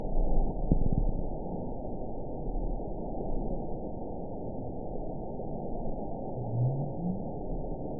event 922342 date 12/30/24 time 01:33:30 GMT (5 months, 2 weeks ago) score 9.53 location TSS-AB04 detected by nrw target species NRW annotations +NRW Spectrogram: Frequency (kHz) vs. Time (s) audio not available .wav